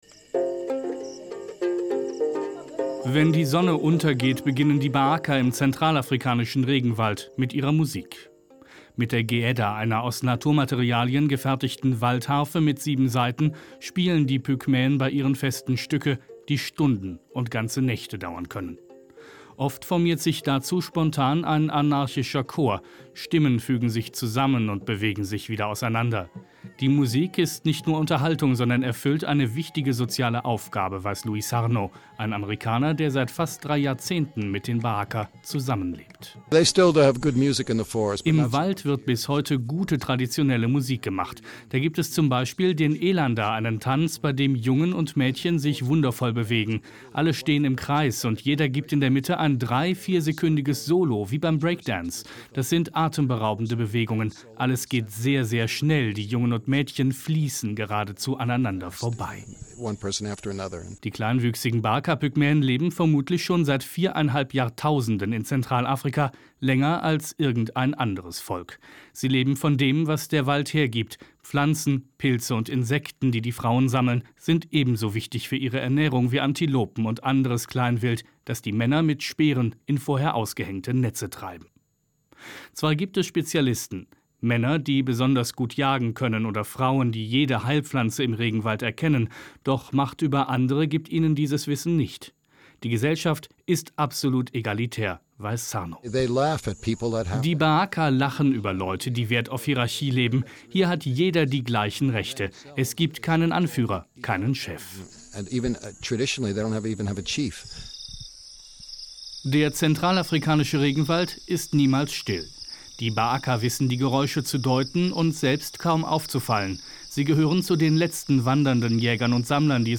Mein Beitrag auf SWR 1 vom 2.7.12 (mit Pygmäenmusik): Jagen, sammeln, tanzen – Die BaAka-Pygmäen im zentralafrikanischen Regenwald kommen ohne Geld (noch) gut aus